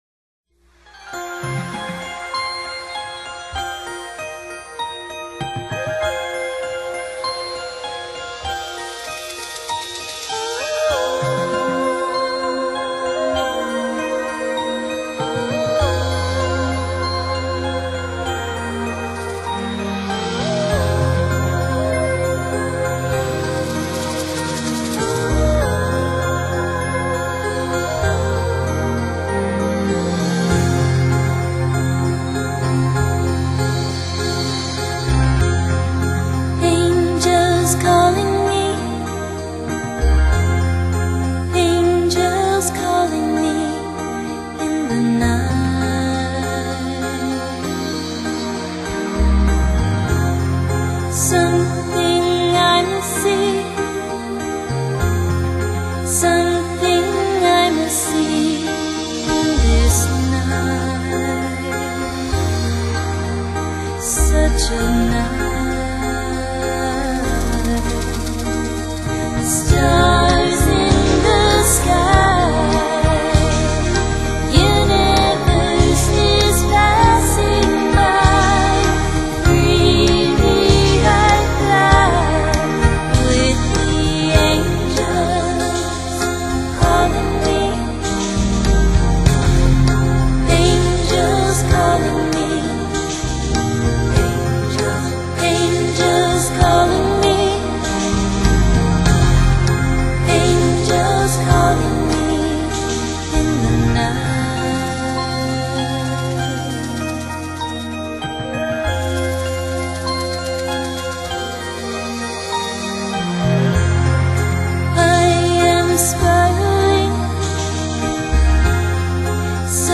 新世纪音乐